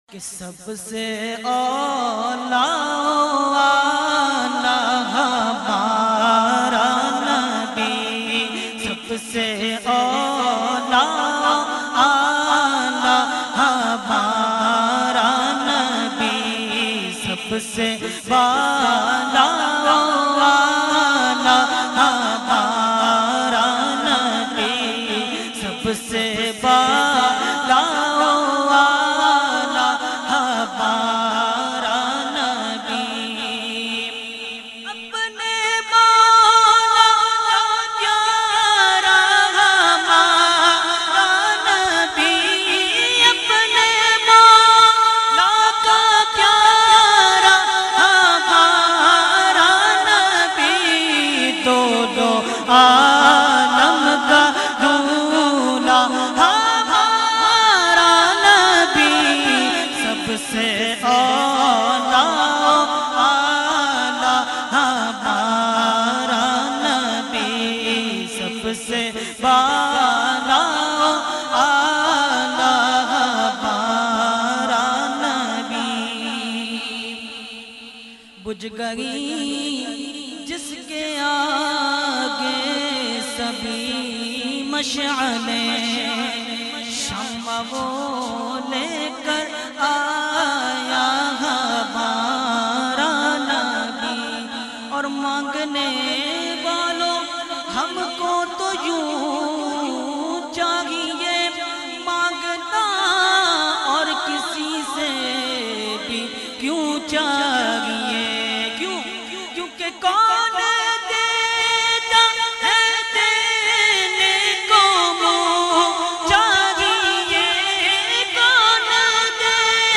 9 Roza Mehfil e Muharram ul Haram held on 1st Muharram ul Haram to 9th Muharram ul Haram at Jamia Masjid Ameer Hamza Nazimabad Karachi.
Category : Naat | Language : UrduEvent : Muharram 2021